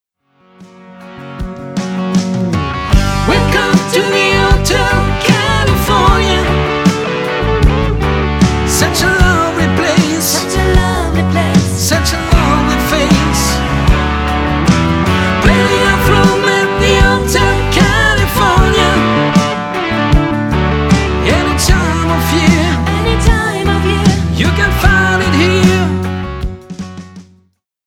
Partyband aus Neuried Altenheim Music was our first love...